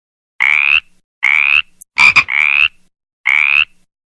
Рев тигра 29 сент. 2023 г. Рев тигра Скачать (169 скачали) Дельфин.